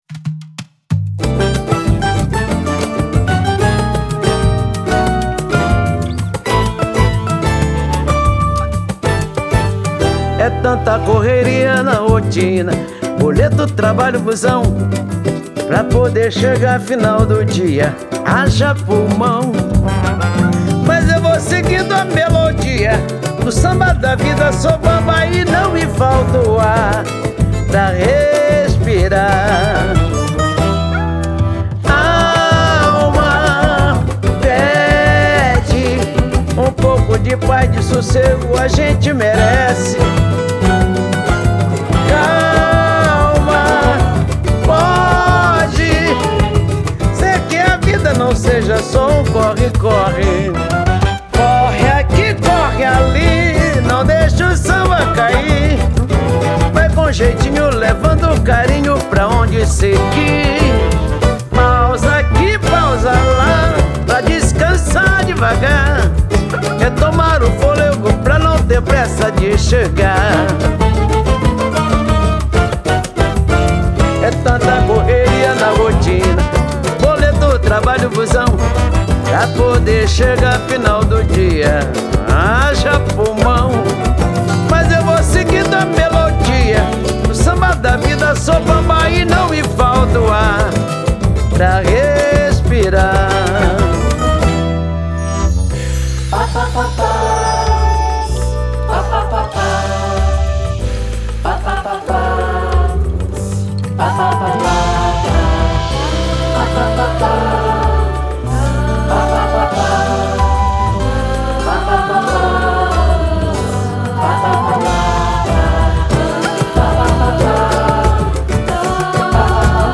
a cantora é acompanhada pelo coral de pacientes atendidos